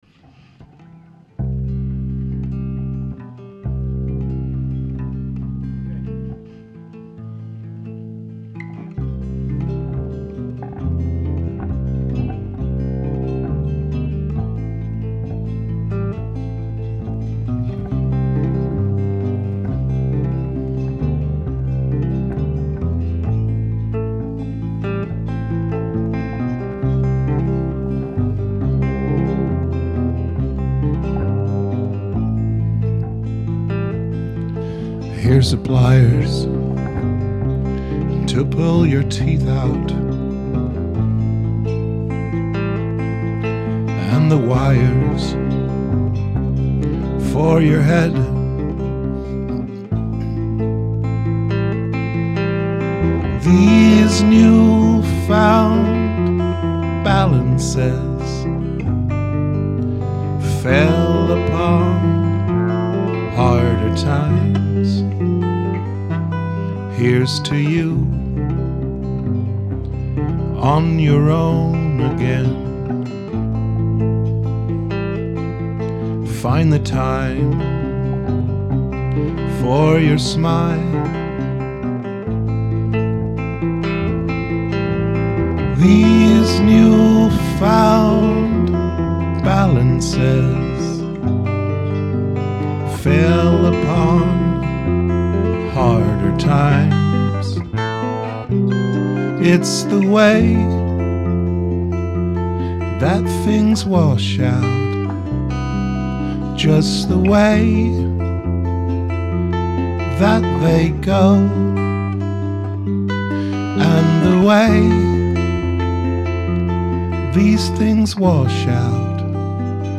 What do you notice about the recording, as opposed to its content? Rehearsals 25.2.2012